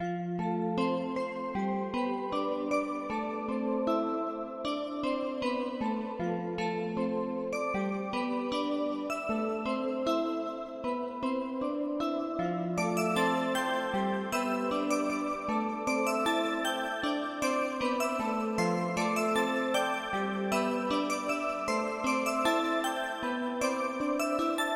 铃铛的钢琴旋律
描述：丰富的小钢琴旋律，希望有人能与之合作，请在评论中留下你的曲目的链接。
Tag: 155 bpm Hip Hop Loops Piano Loops 4.17 MB wav Key : Unknown